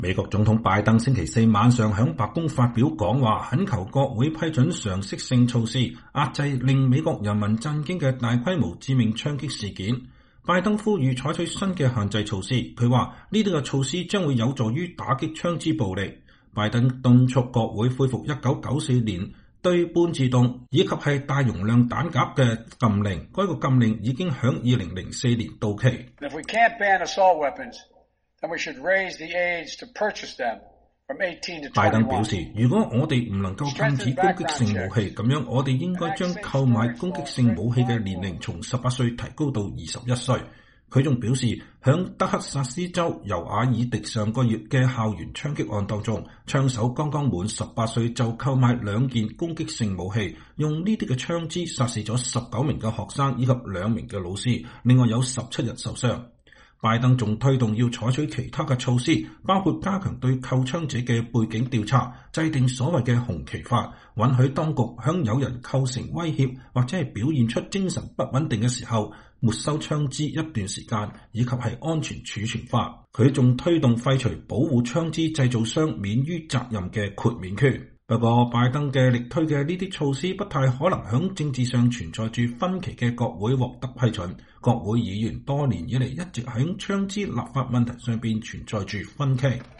2022年6月2日，美國總統喬·拜登在華盛頓白宮東廳就最新一輪大規模槍擊事件發表講話。